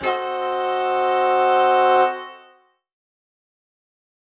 UC_HornSwellAlt_Bdim.wav